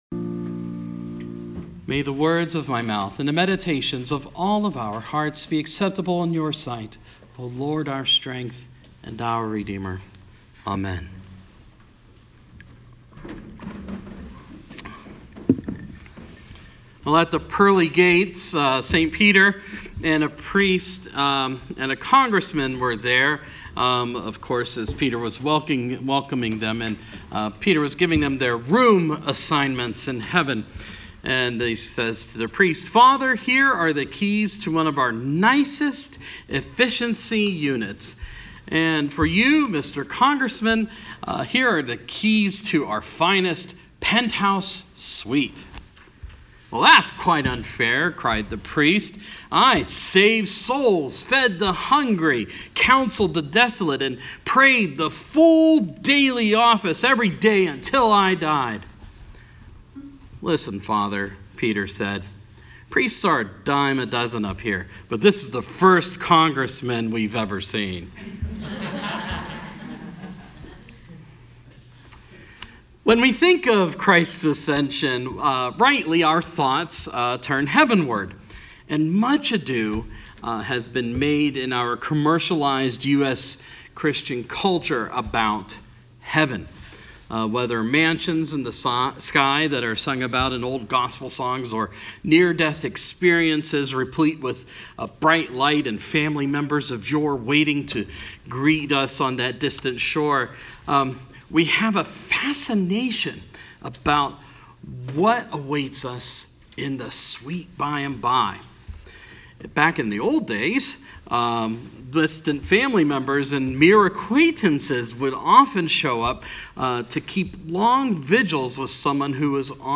Sermon – Eve of Ascension